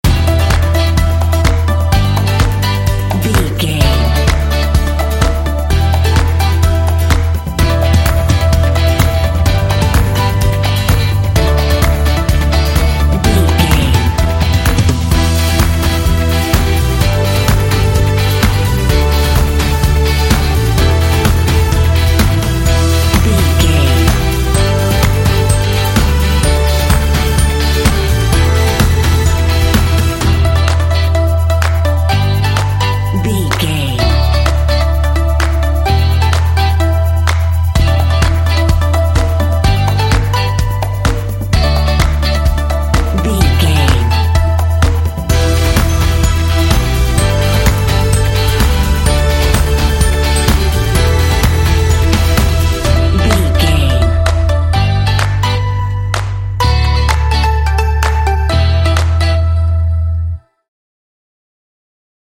Aeolian/Minor
lively
cheerful/happy
electric guitar
bass guitar
synthesiser
drums
synth-pop
alternative
indie